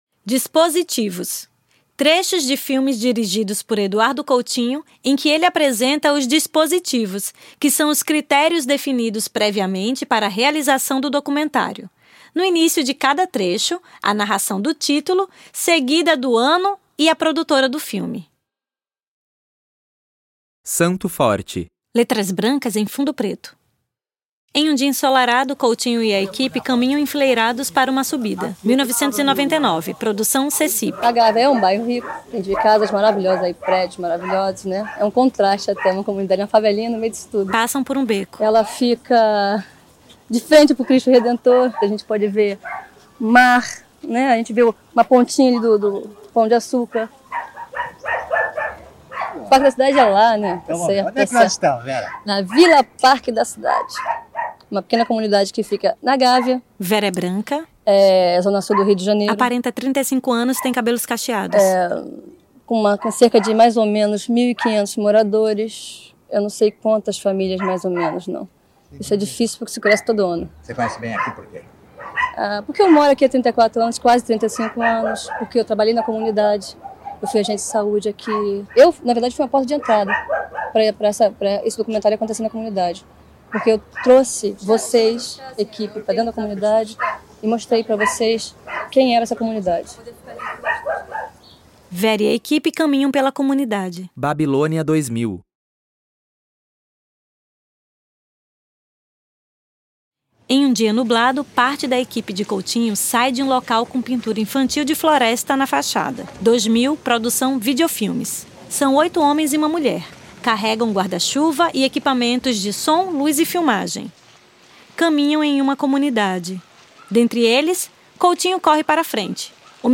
Trechos de filmes dirigidos por Eduardo Coutinho em que ele apresenta os dispositivos, que são os critérios definidos previamente para a realização do documentário. No início de cada trecho, a narração do título, seguida do ano e produtora do filme.